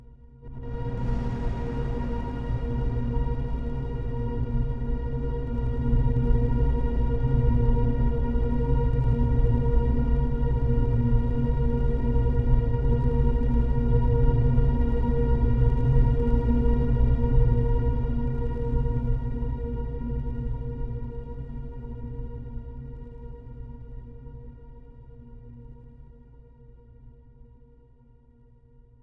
cinematic deep bass rumble
tension